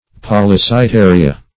Search Result for " polycyttaria" : The Collaborative International Dictionary of English v.0.48: Polycyttaria \Pol`y*cyt*ta"ri*a\, n. pl.